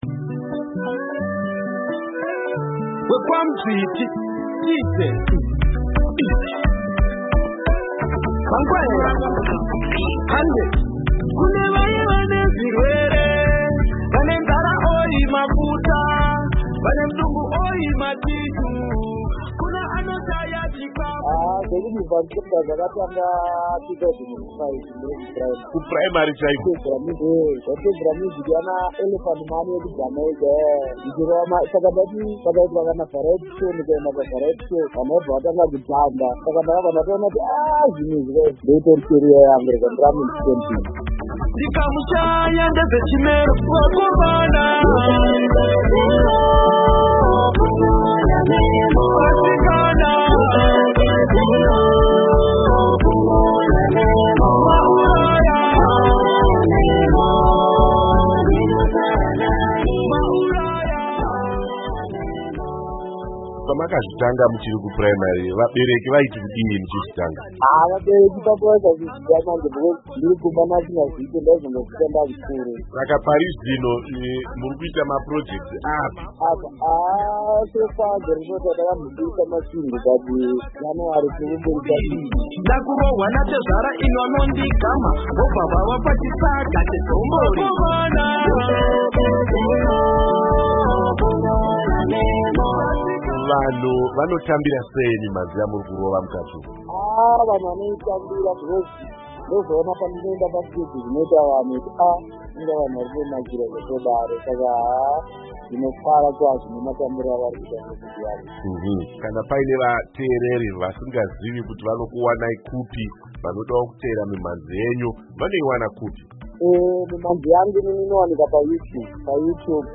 Muchirongwa chedu chemagitare chesvondo rino, taita hurukuro nemuimbi aita mukurumbira achiimba musambo weZim Dancehall